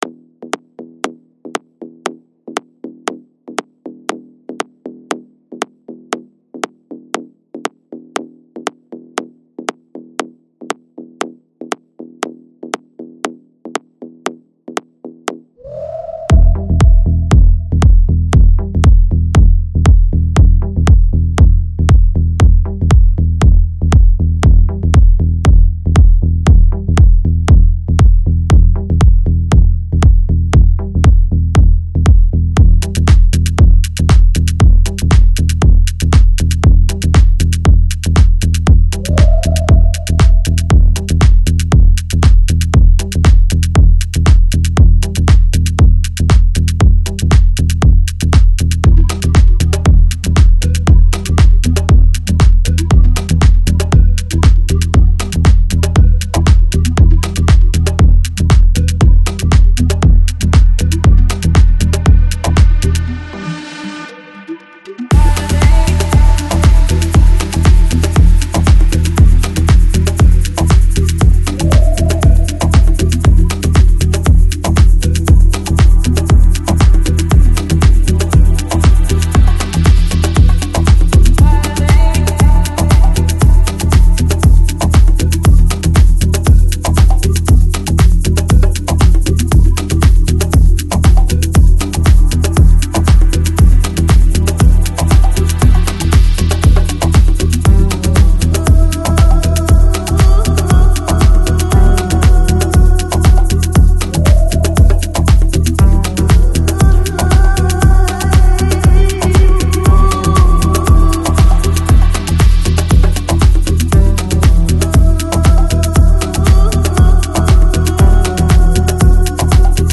World, Ethnic, Oriental Folk Издание